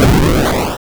Bomb_Explosion.wav